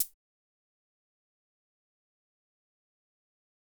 Closed Hats
quik hat .wav